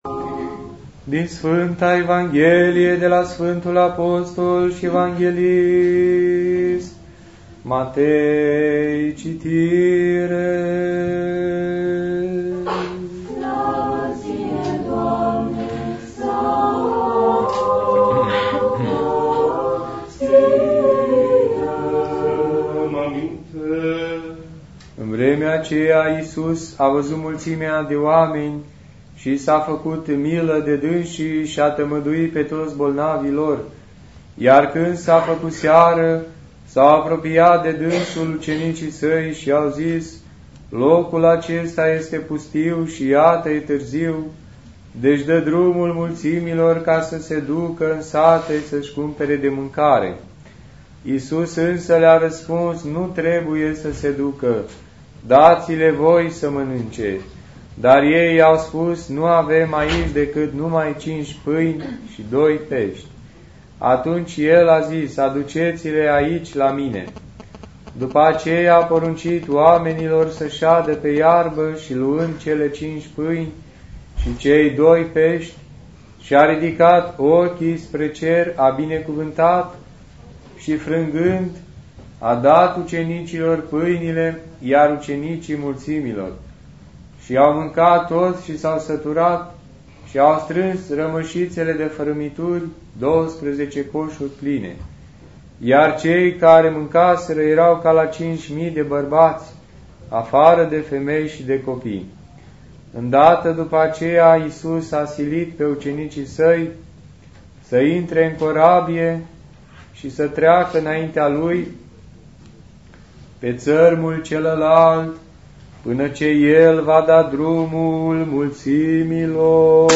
Dumnezeiasca Liturghie